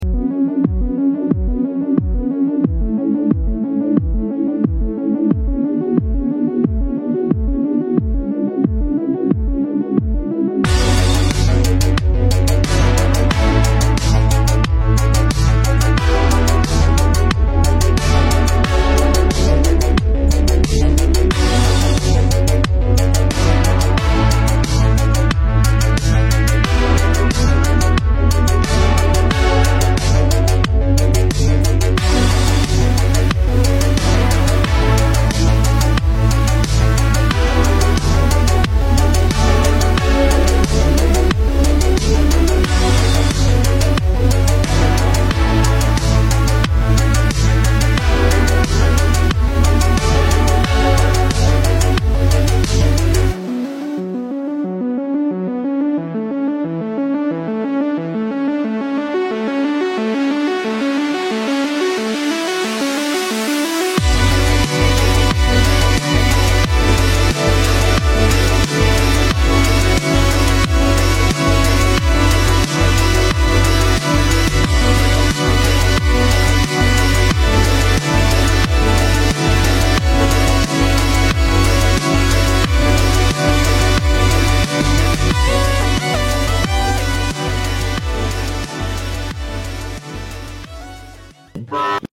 War Of The Worlds Tripod sound effects free download